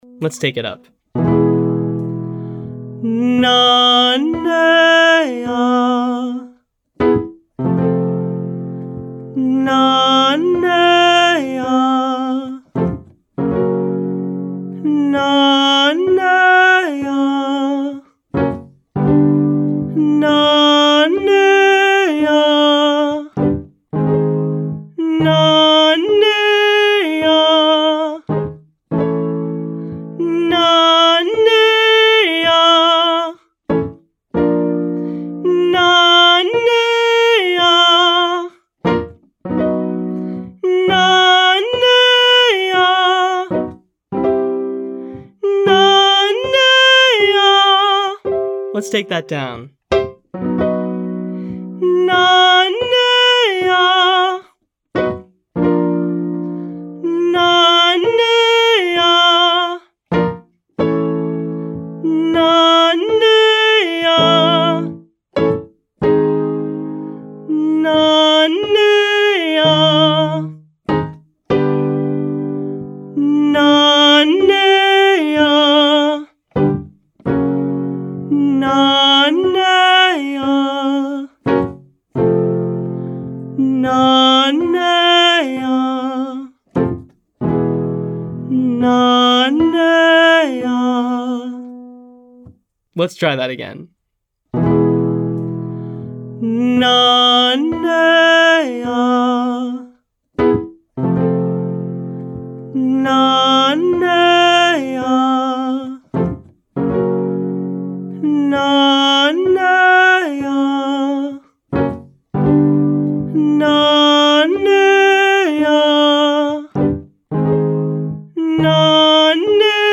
Exercise: Chest-dominant mix - Nuh Nay Uh (5-1-5 from below ) B
Pop Daily Exercises Lesson 5B